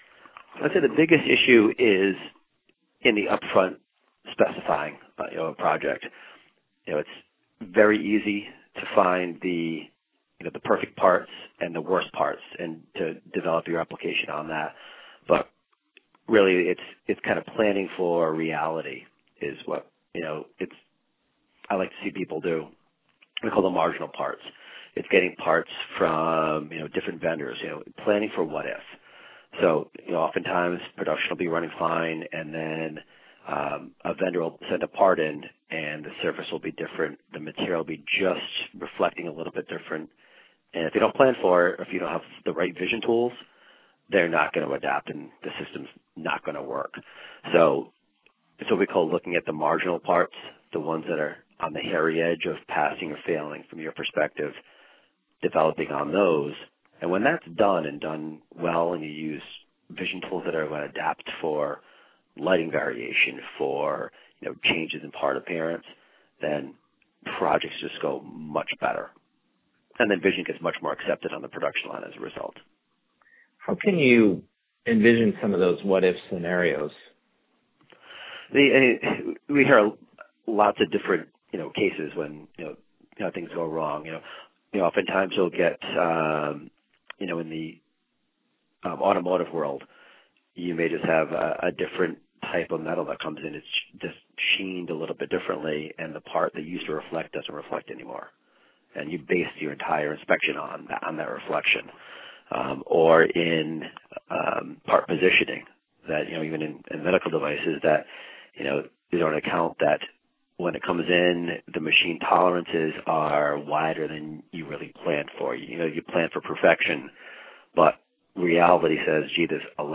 expert interview